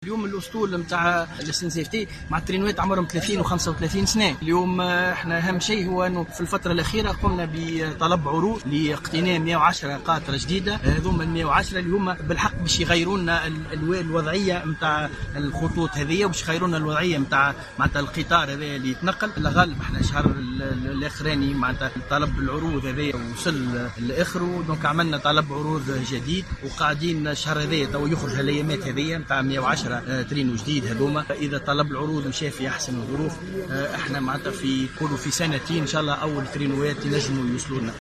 وقال بن أحمد في تصريح للجوهرة أف أم، لدى زيارته اليوم الاثنين لموقع حادث القطار الذي جد في مدينة القلعة الكبرى، إن تونس ستقتني 110 قاطرات جديدة، مما سيساهم في تحسين وضعية النقل الحديدي وتحسين الخدمات المقدمة في مختلف الخطوط.